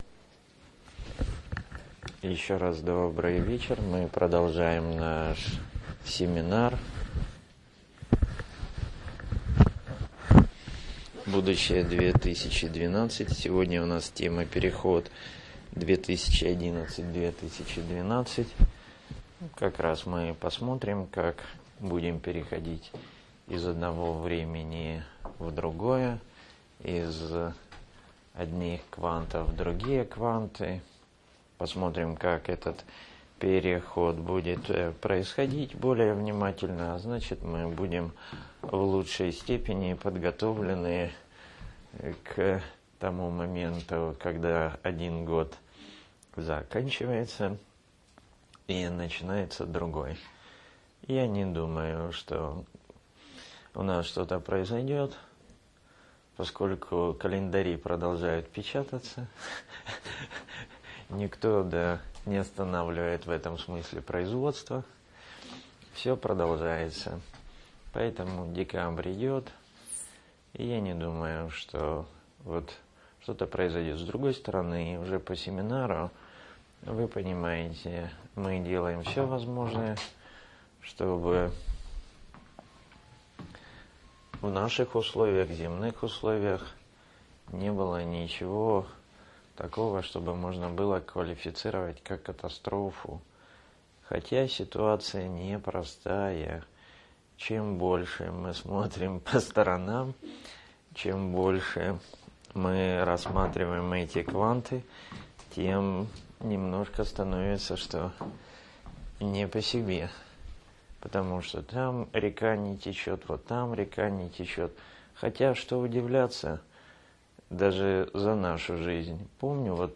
День третий. Переход 2011-2012 - часть1. Медитация с растворами.